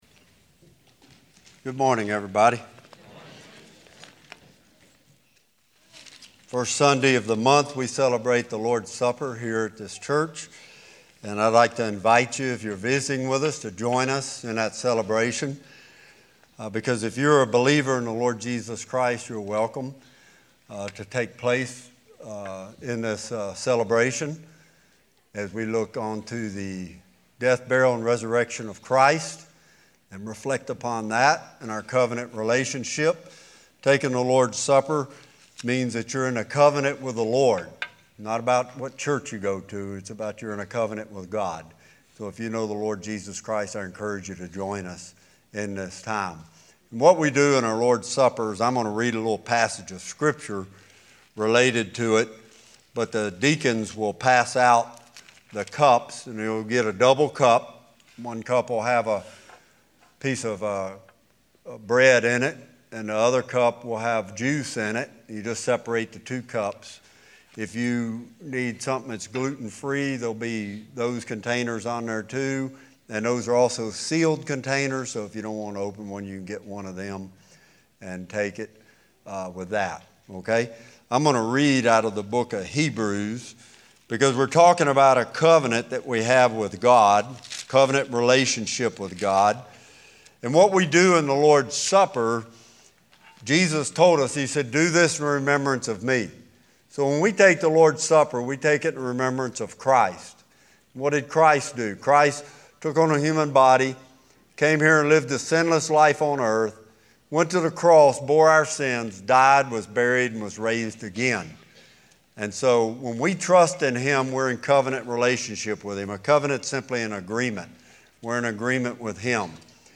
Fellowship of Huntsville Church Sermon Archive